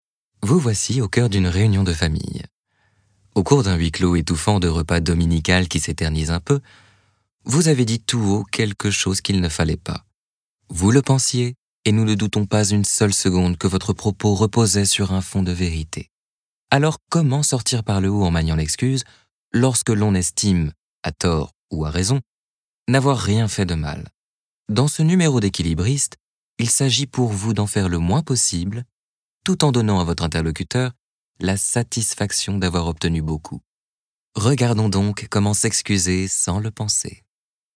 Voix off
Demo Narration Tuto
25 - 35 ans - Baryton-basse